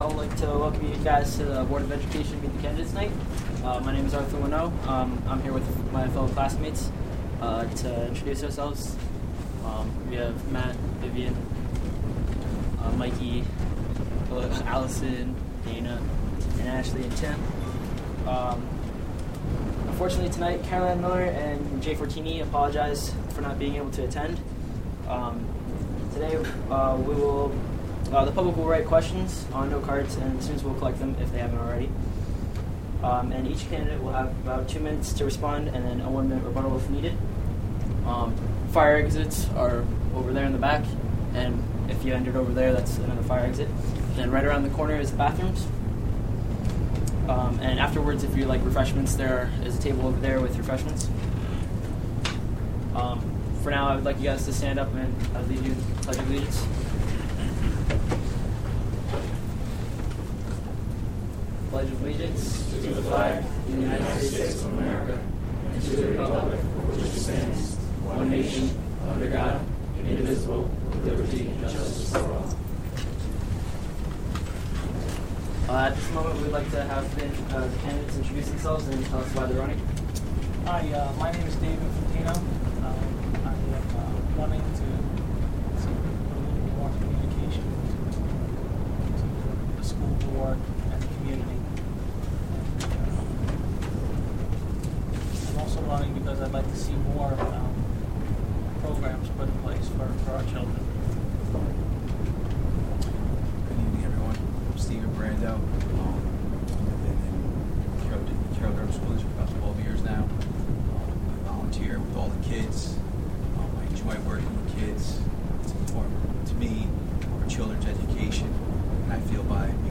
Cairo-Durham School District Meet the Candidates Forum: May 15, 2014: 7pm- 8pm
(Audio) May 15, 2014 events Cairo-Durham School District Meet the Candidates Forum : May 15, 2014: 7pm- 8pm Cairo-Durham High School Hosted by Cairo-Durham High School Participation in Government class.